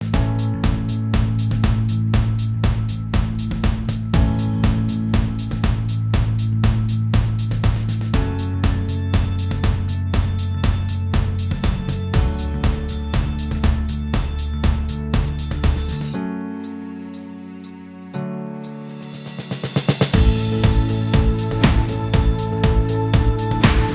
Minus All Guitars Pop